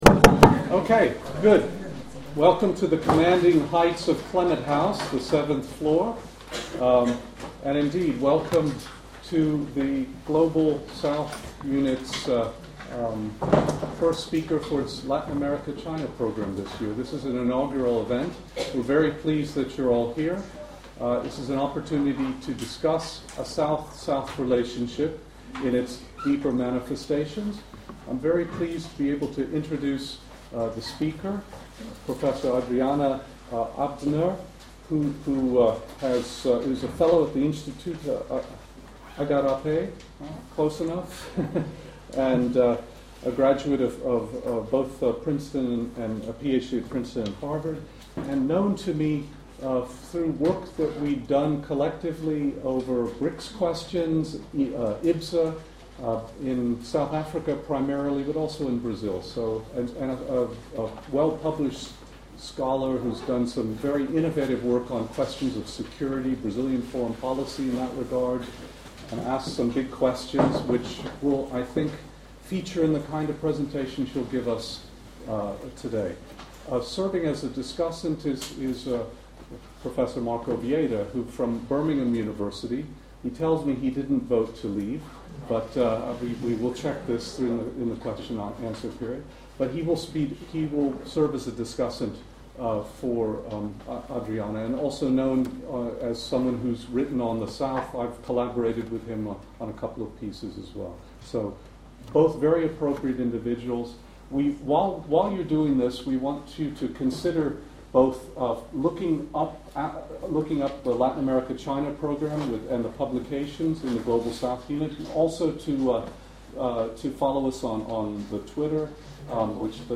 PUBLIC LECTURE: GSU & DEPT INTERNATIONAL RELATIONS From Tailwinds to Headwinds. Can Latin America Weather the Storm?
Venue: Wolfson Theatre, New Academic Building, LSE campus President Garcia explored the role of development banks in meeting the current challenges that Latin America faces in the 21st century.